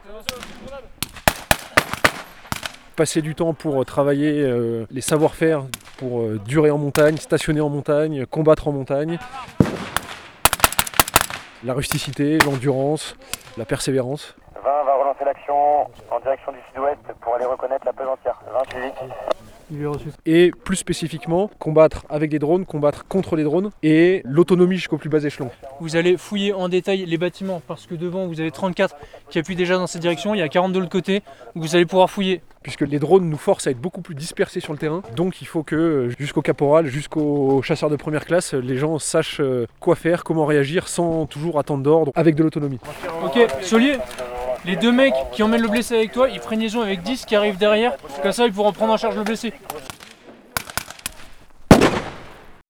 ITC Tout sonore-Exercice Galbert 27e BCA Glières
ITC Tout sonore-Exercice Galbert 27e BCA Glières.wav